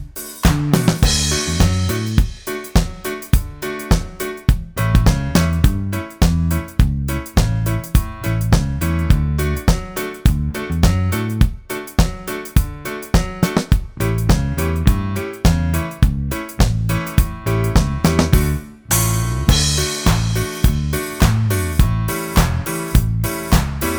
no bass T.V. Themes 3:42 Buy £1.50